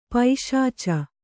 ♪ paiśaca